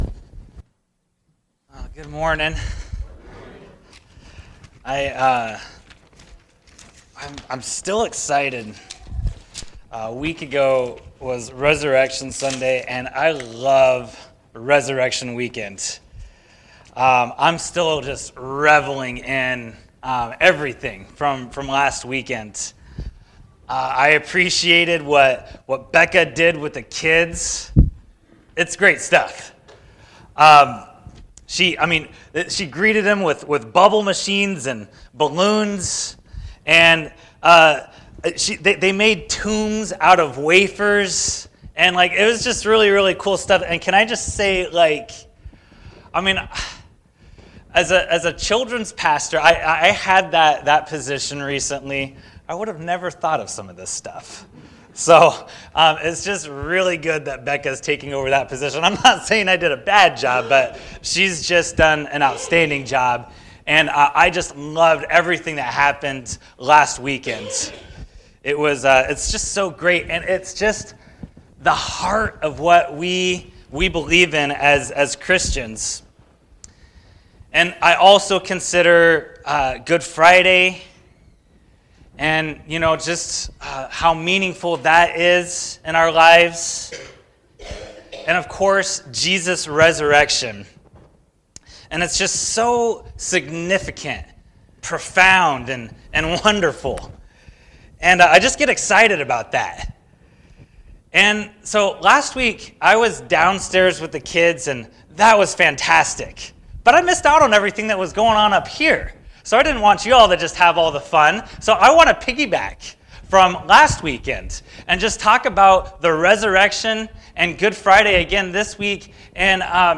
Sermons | Mountain View Alliance